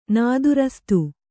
♪ nādurastu